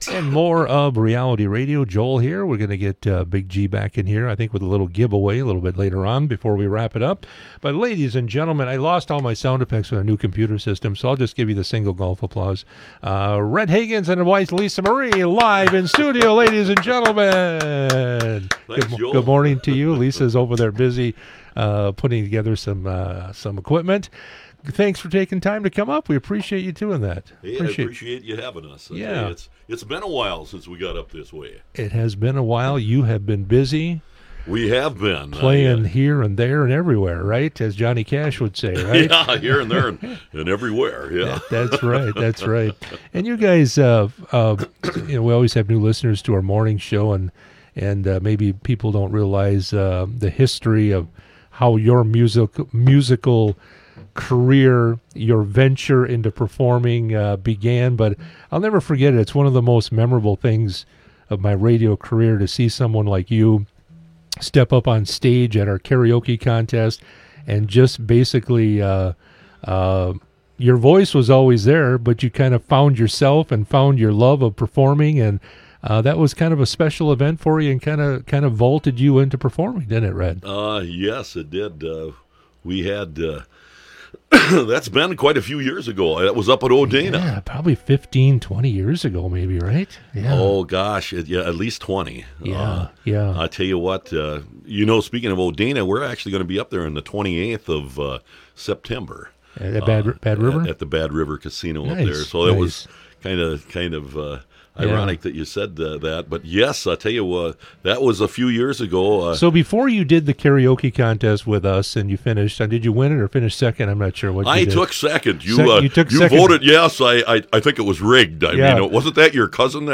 Interviews and special broadcasts from 98Q Country in Park Falls.
98q interviews